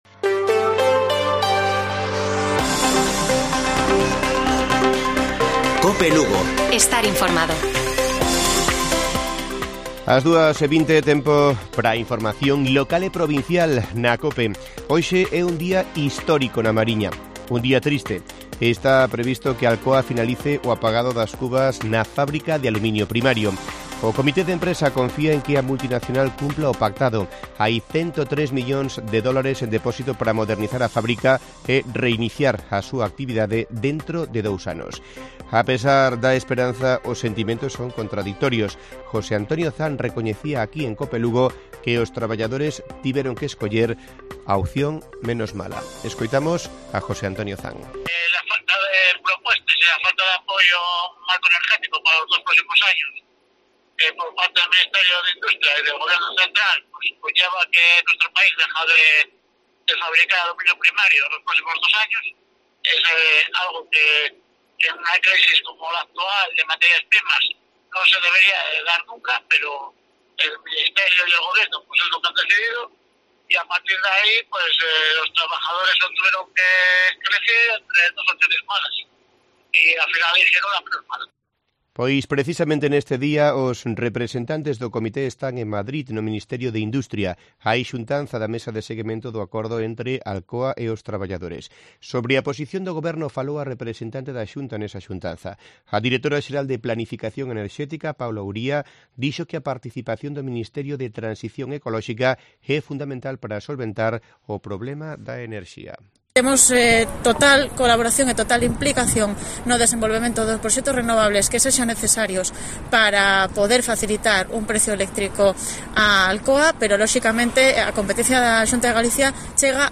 Informativo Mediodía de Cope Lugo. 20 de enero. 14:20 horas